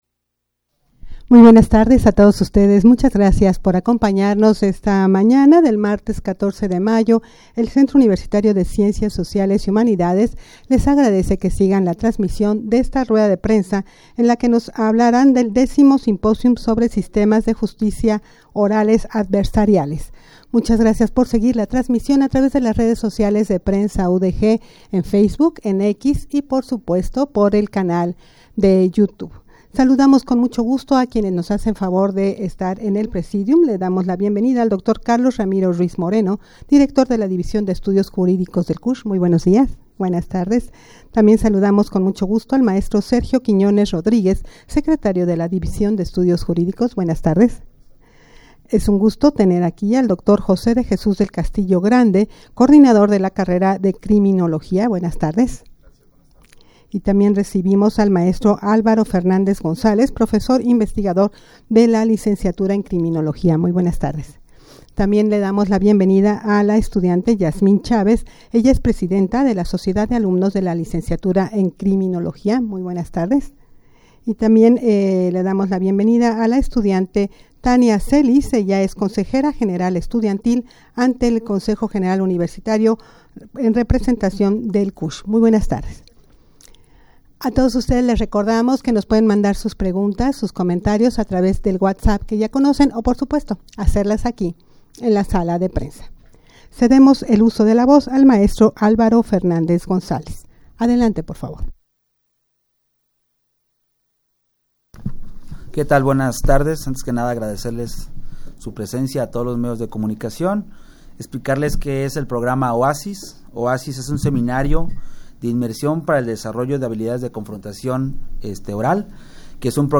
Audio de la Rueda de Prensa
rueda-de-prensa-para-la-presentacion-del-decimo-simposium-sobre-sistemas-de-justicia-orales-adversariales.mp3